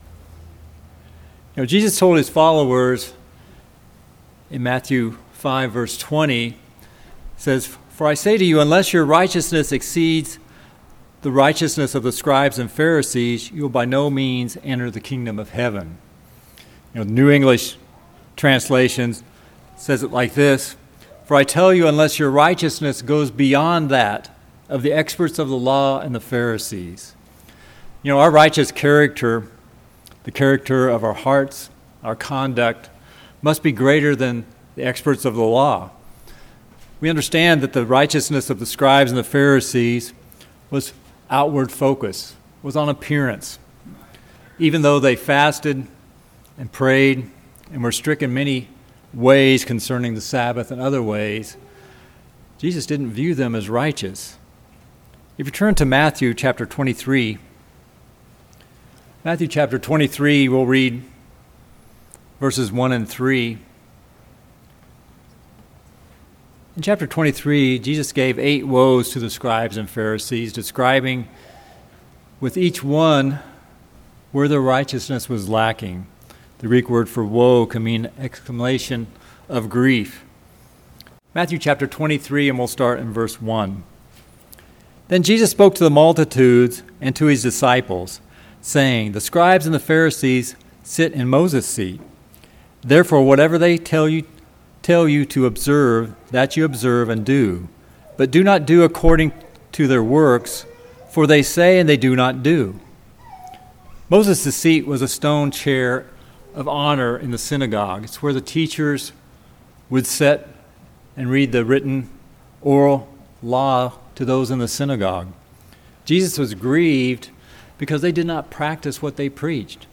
He was accusing the Pharisees of being very careful about other aspects of the law while neglecting these important ones. In this sermon we will examine what God requires of us concerning justice, mercy, and faith.